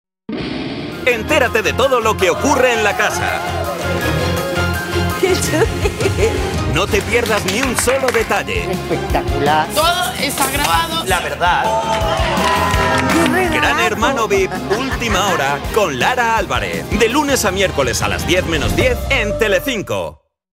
Programas de TV
Soy locutor y actor de doblaje en España.
Neumann Tlm 103